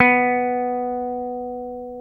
Index of /90_sSampleCDs/Roland L-CDX-01/GTR_Dan Electro/GTR_Dan-O 6 Str